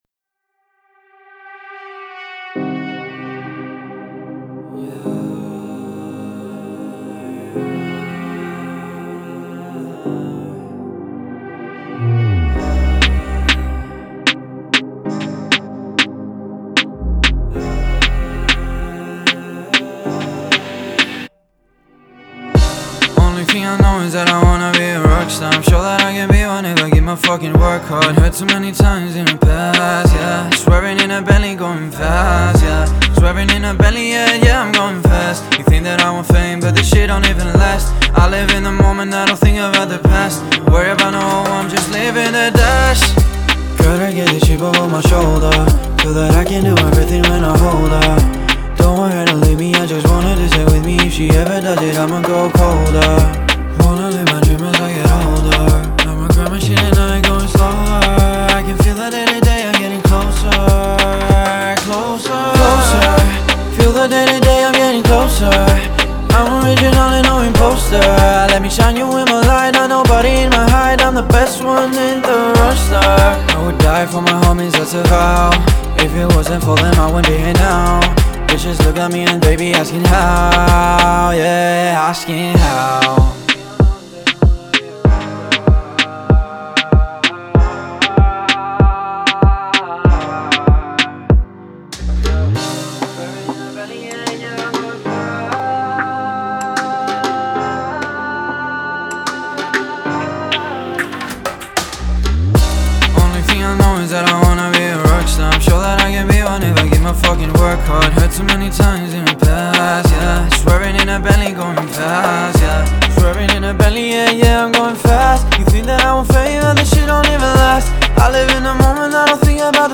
это энергичная трек в жанре хип-хоп